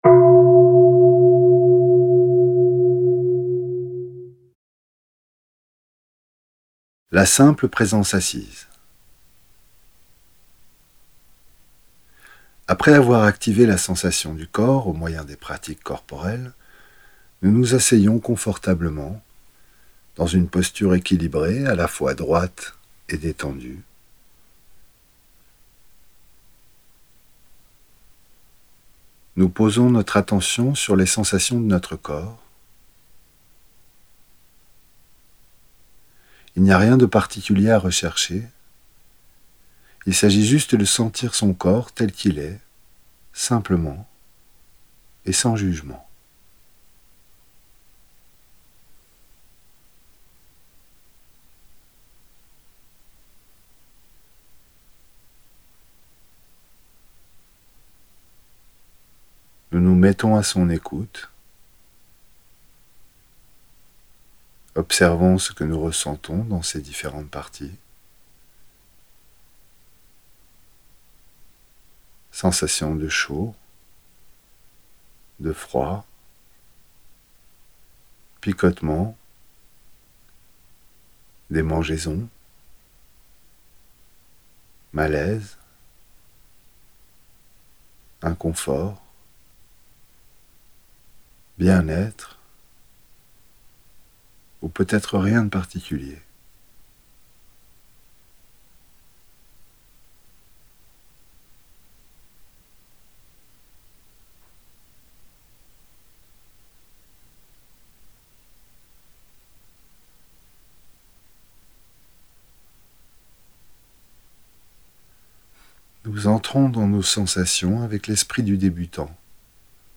Audio homme - Pratique 7
4.ETAPE-1-AUDIO-6-P7-HOMME.mp3